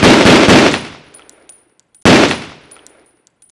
GUN SHOT 1-R.wav